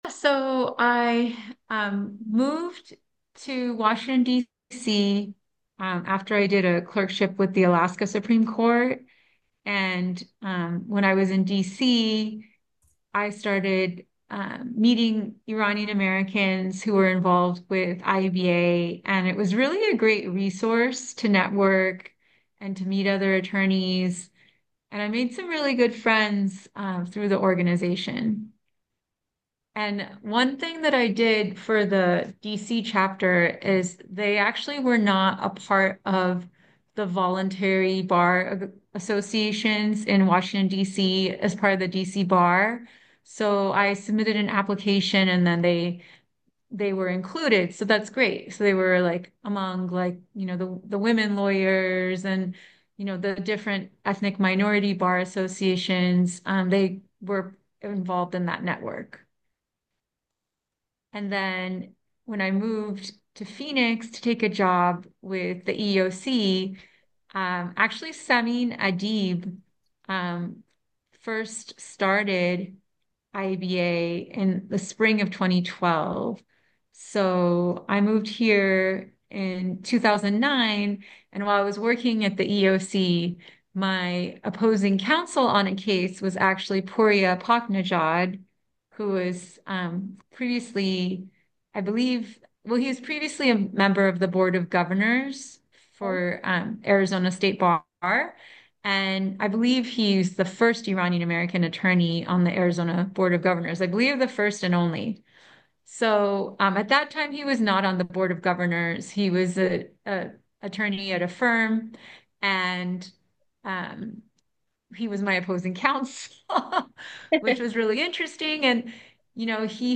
Interview Clips